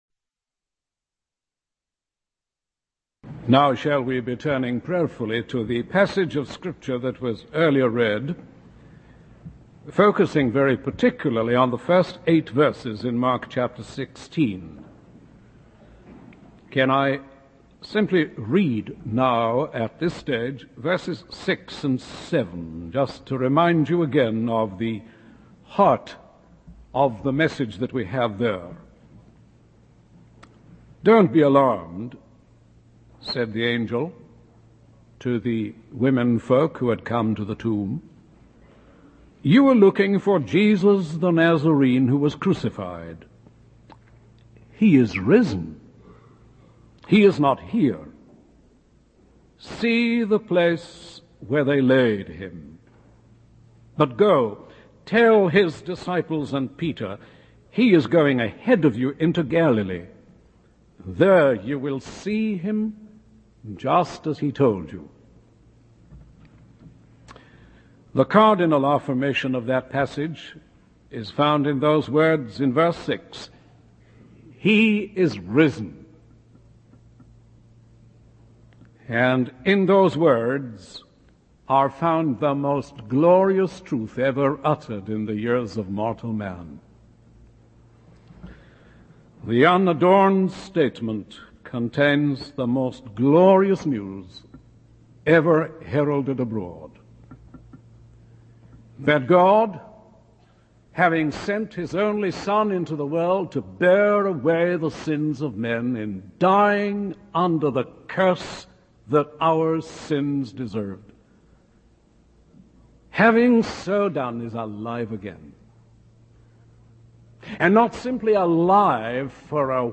In this sermon, the speaker emphasizes the significance of the empty tomb and the resurrection of Jesus.